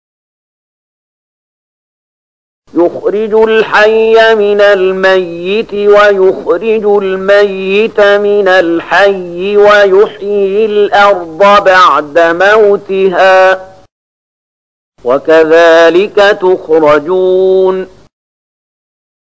030019 Surat Ar-Ruum ayat 19 dengan bacaan murattal ayat oleh Syaikh Mahmud Khalilil Hushariy: